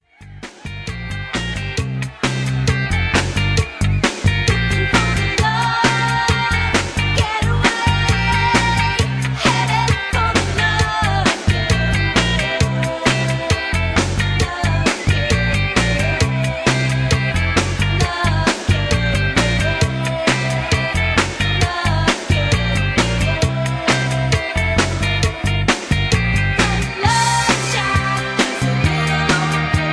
Karaoke Mp3 Backing Tracks
karaoke